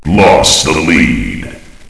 flak_m/sounds/announcer/int/lostlead.ogg at 098bc1613e970468fc792e3520a46848f7adde96